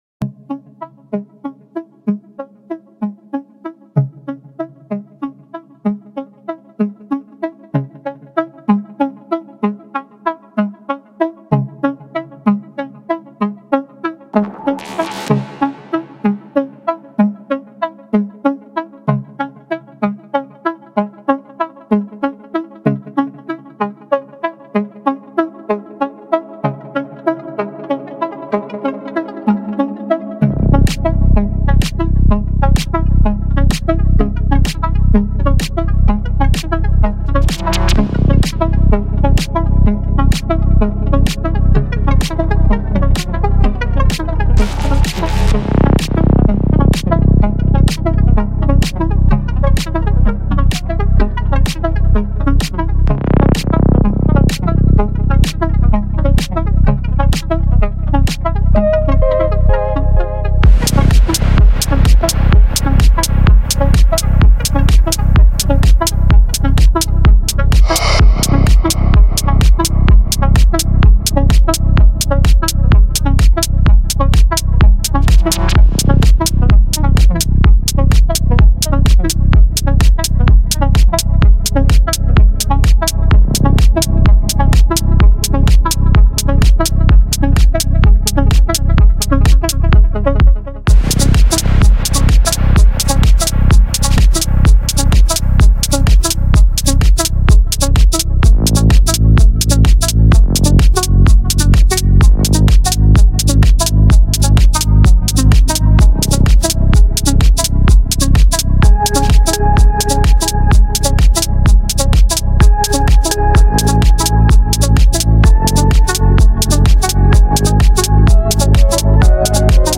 триольками нормально так вписывается в бит)